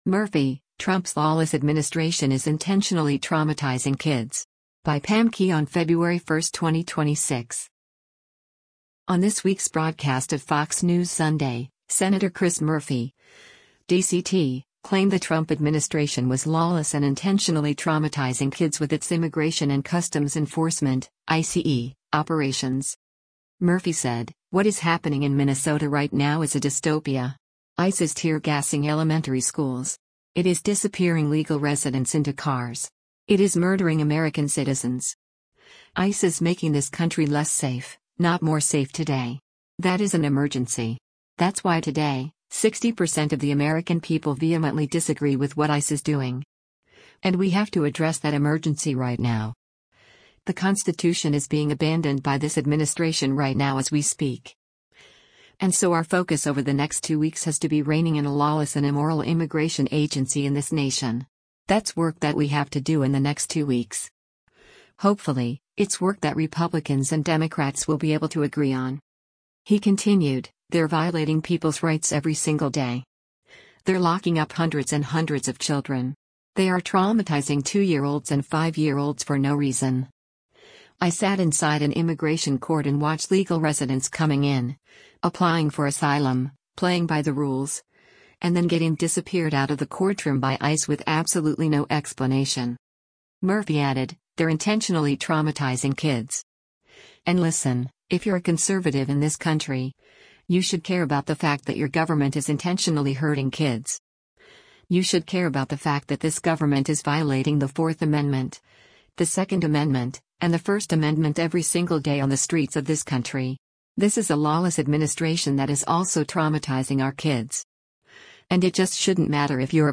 On this week’s broadcast of “Fox News Sunday,” Sen. Chris Murphy (D-CT) claimed the Trump administration was “lawless and “intentionally traumatizing kids” with its Immigration and Customs Enforcement (ICE) operations.